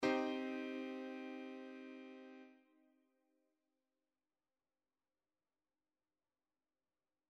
Question 5: Is this chord major or minor?
chord.mp3